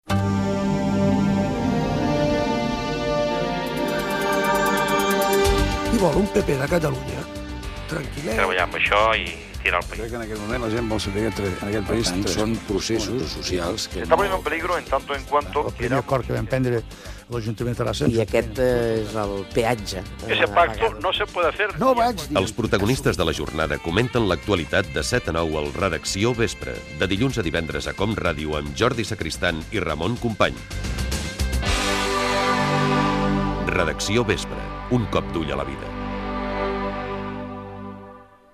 Promoció de l programa